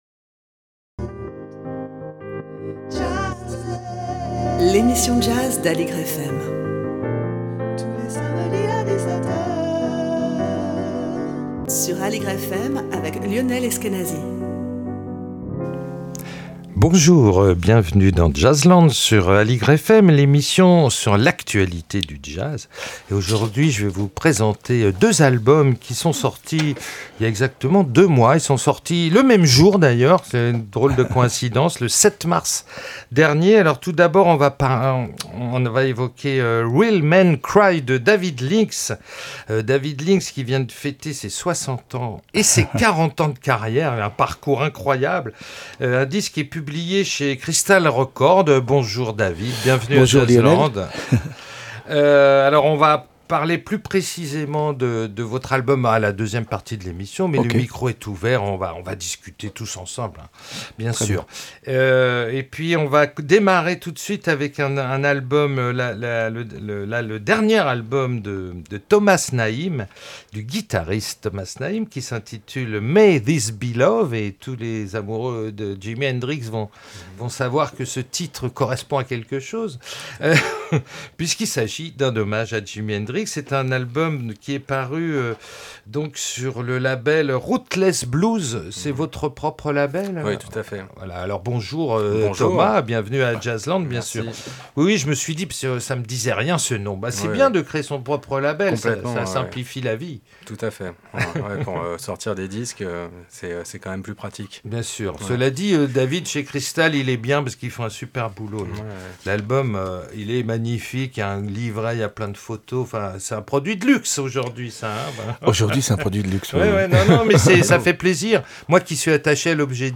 Jazzland Le Jazz sans frontières Animée par deux explorateurs...